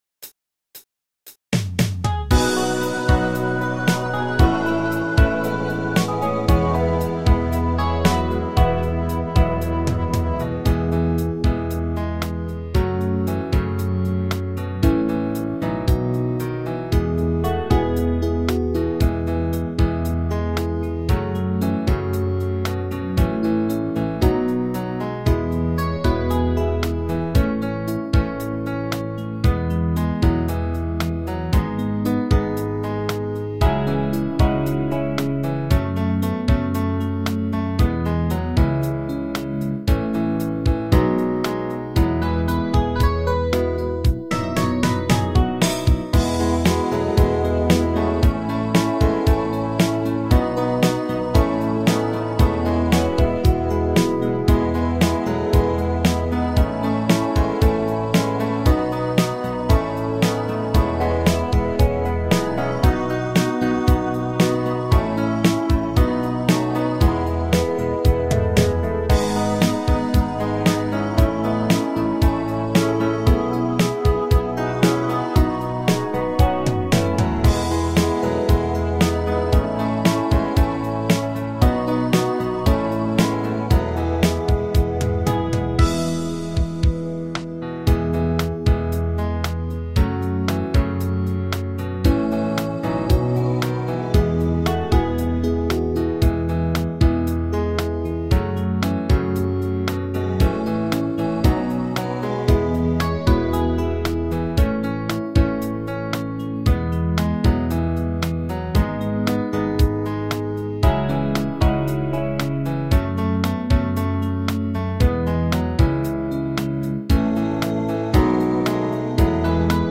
PRO MIDI Karaoke INSTRUMENTAL VERSION
Alpenrock